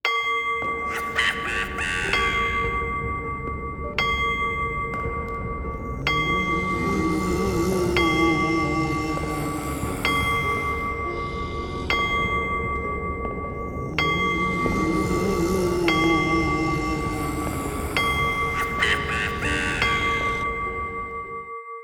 cuckoo-clock-11.wav